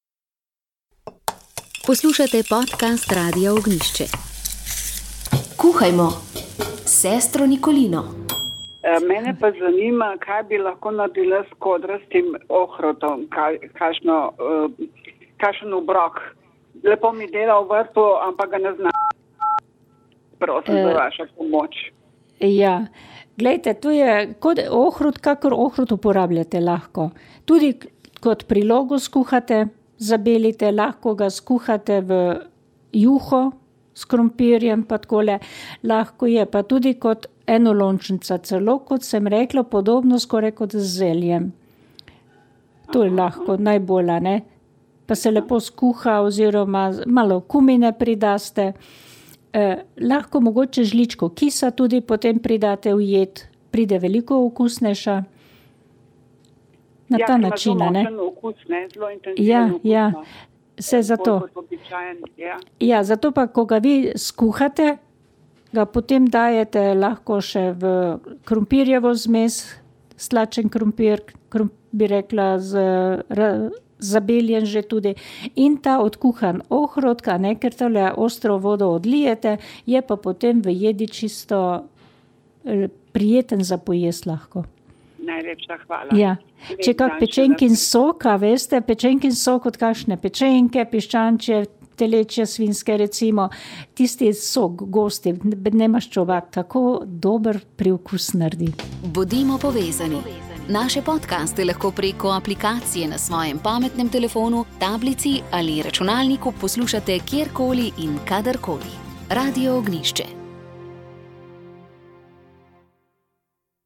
Spanje je že tako in tako nekakšna splošna težava, premik ure pa vse skupaj še nekoliko poslabša, tudi zato smo se odločili, da v oddaji Sol in luč preberemo nekaj odlomkov iz odlične knjige z naslovom Zakaj spimo, ki jo je napisal Dr. Matthew Walker, profesor nevroznanosti in psihologije na univezi Berkley v Kaliforniji. Premalo spanja vpliva na mnogo zdravstvenih dejavnikov, tudi na obolelost za rakom, sladkorno bolezen in celo na prekomerno težo.